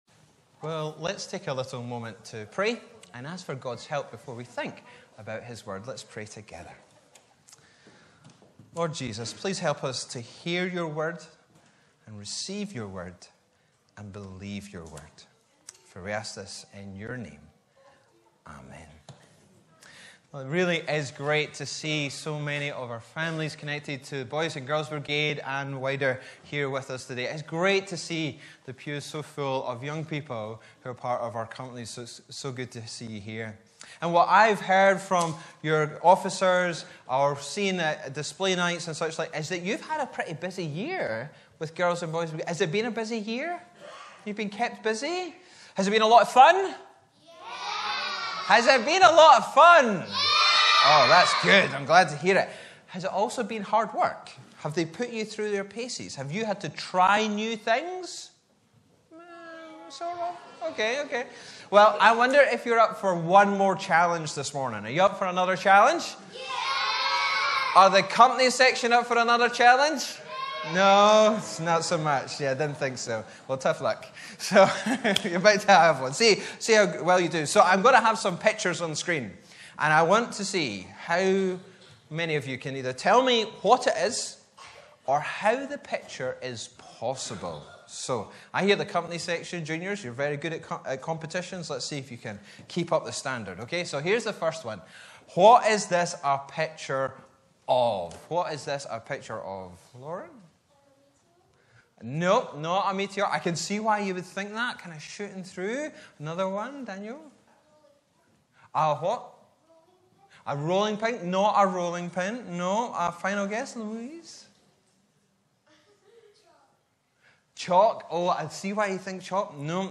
Bible references: John 12:12-19 & 1 Corinthians 1:18 & 25 Location: Brightons Parish Church
Sermon keypoints